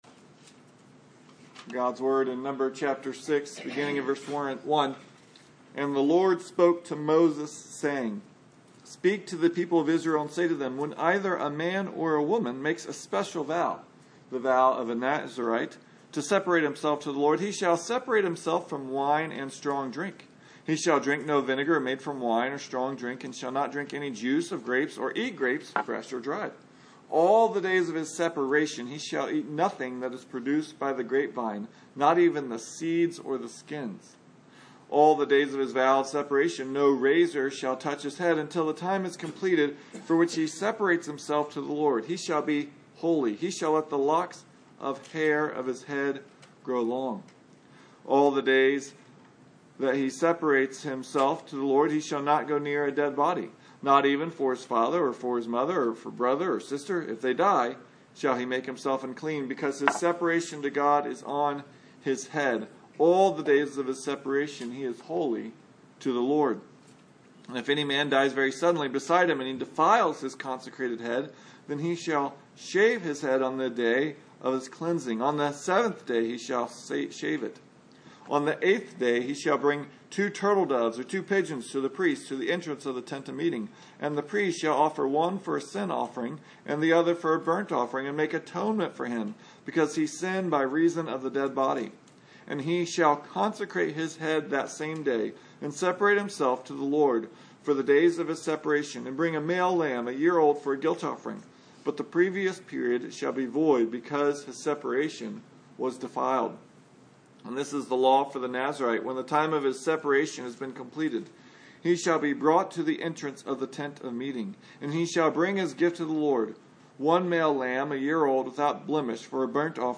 Numbers 6:1-21 Service Type: Sunday Morning Nazirites.